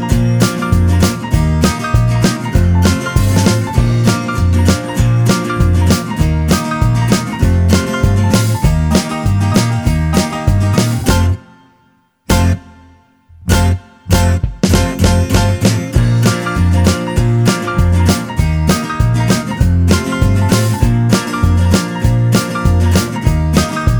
No Backing Vocals Rock 'n' Roll 3:08 Buy £1.50